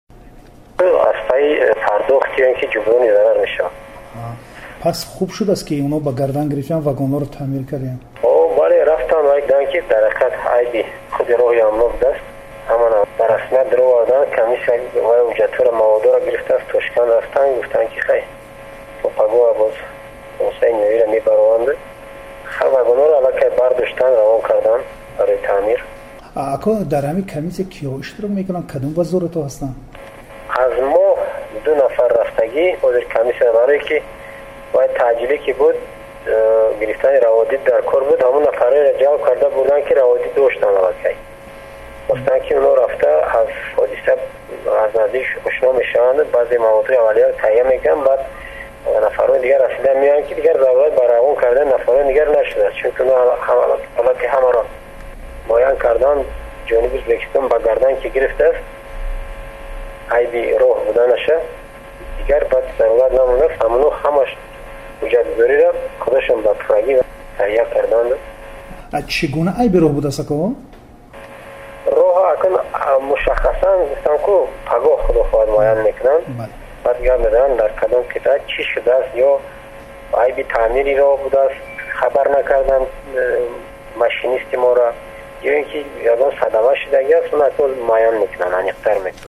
Сӯҳбат